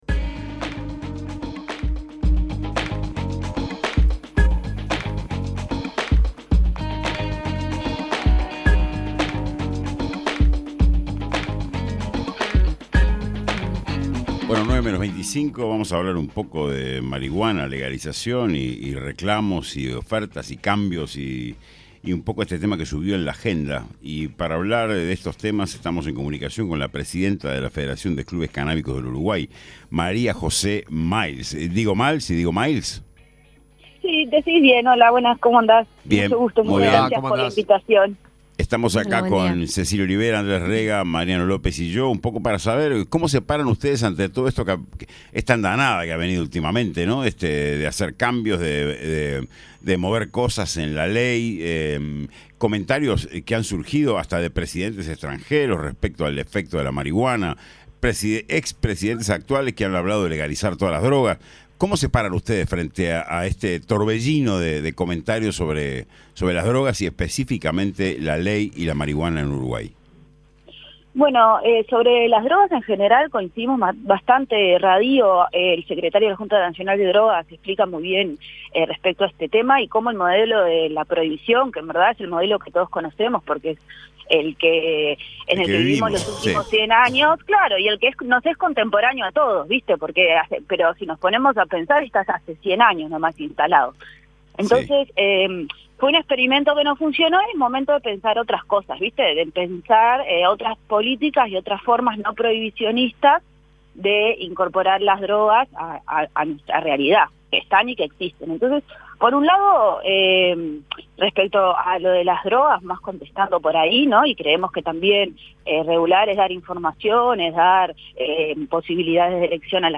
ENTREVISTA-CANNABIS-ENTERA-25-DE-7.mp3